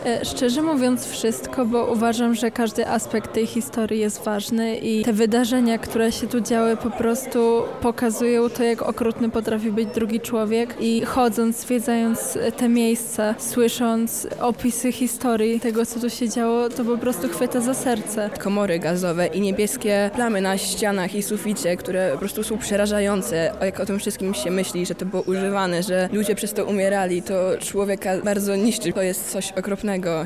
Wszystko dla nas było poruszające – wskazują wzruszone uczestniczki wymiany
Uczestniczki